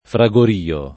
fragorio [ fra g or & o ] s. m. — es. con acc. scr.: il rapido fragorìo scalpitante di qualche vettura frettolosa [ il r # pido fra g or & o S kalpit # nte di k U# lke vvett 2 ra frettol 1S a ] (Pirandello)